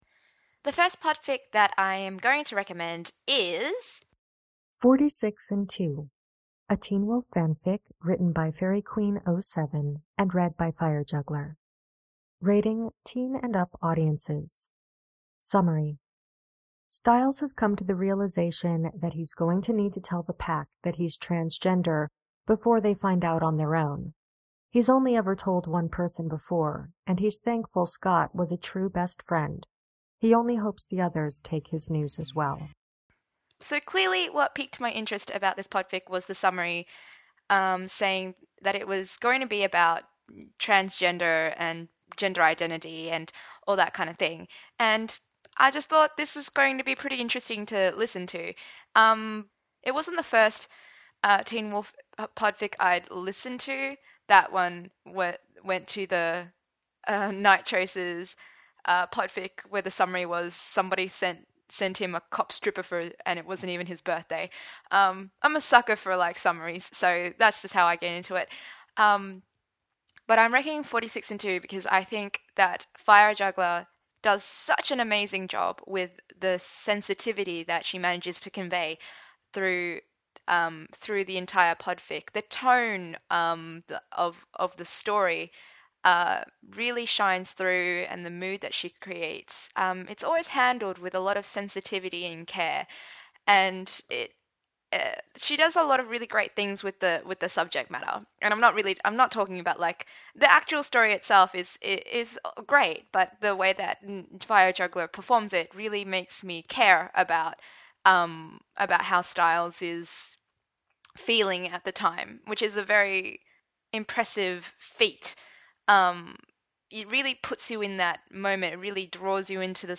I have done a podcast for each rec, where I flail about about how much about how much I like it and why, and also include little clips of each podfic, which you can stream directly, download individually, or download the whole lot in m4b format.